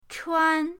chuan1.mp3